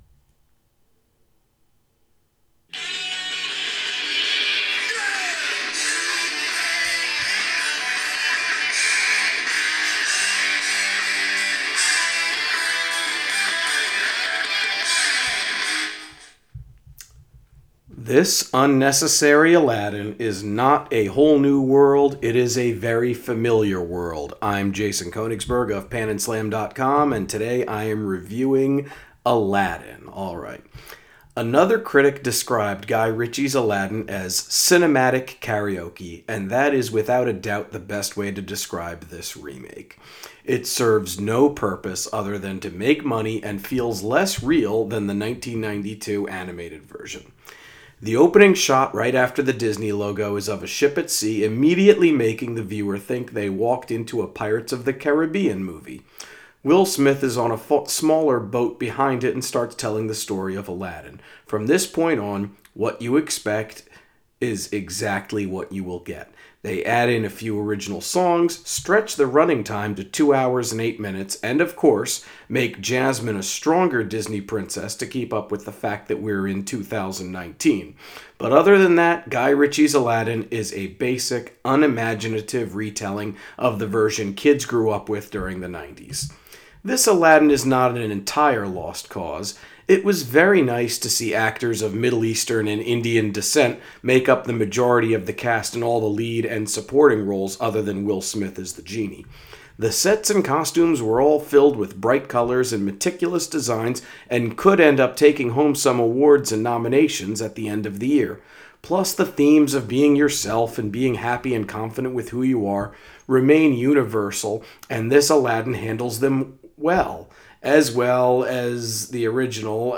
Movie Review: Aladdin